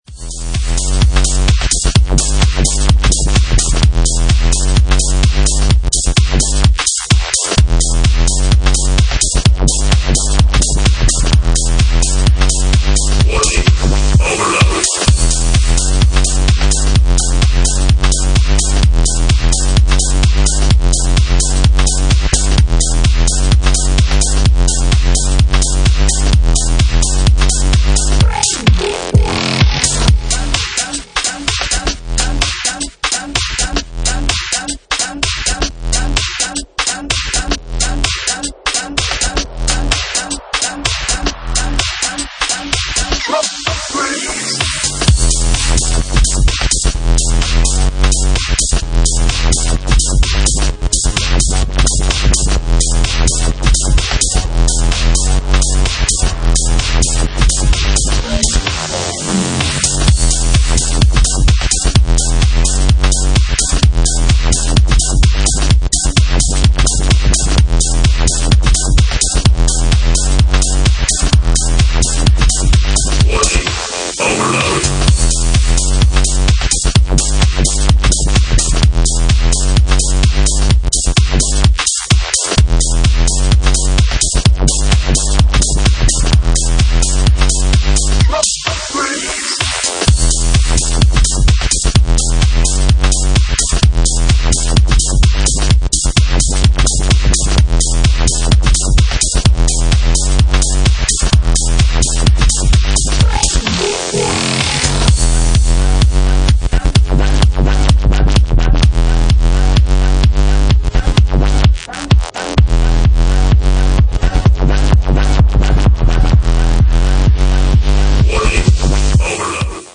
Genre:Jacking House